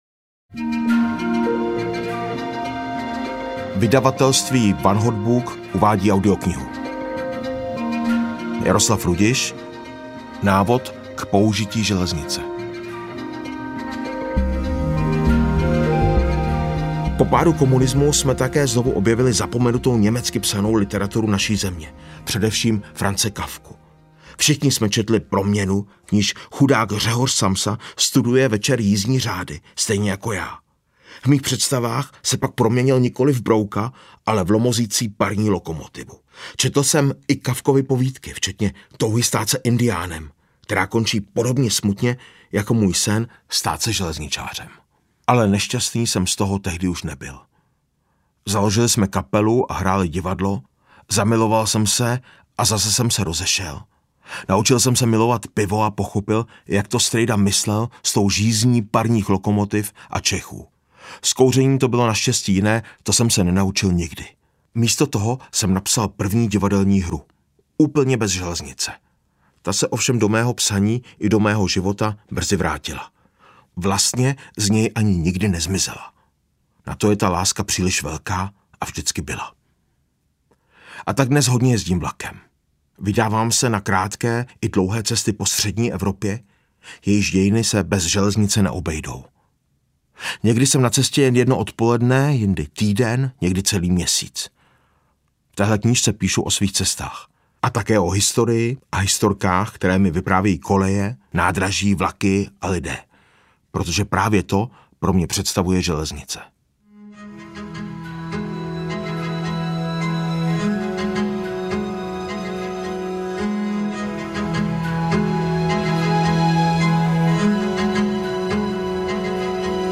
Návod k použití železnice audiokniha
Ukázka z knihy
• InterpretJaroslav Rudiš